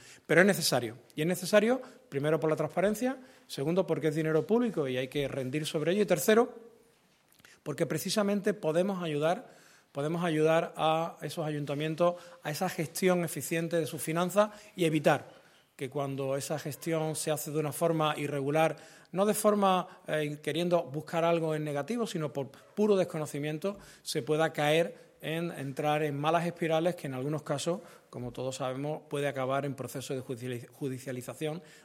Corte de Manuel Alejandro Cardenete